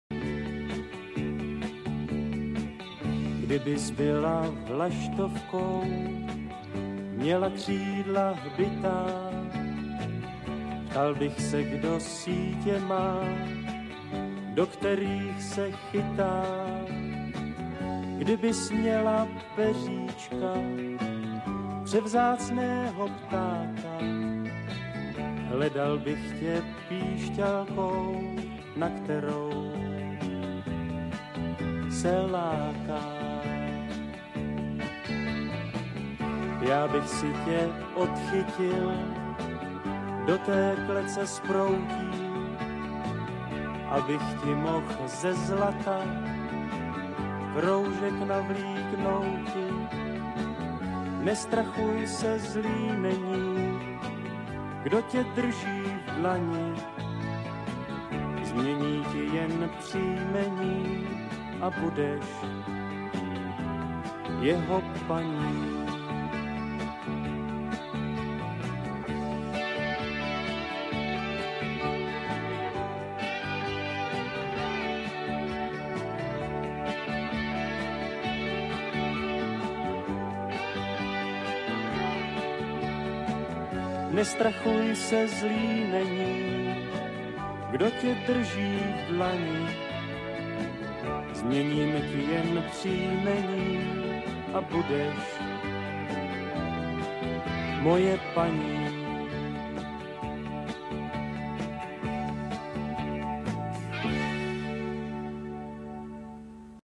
kolobezka_prvni_-kdybys_byla_vlastovkou_-_pisnicka.mp3